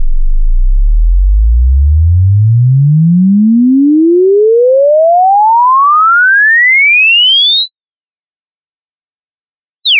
sweep.Wav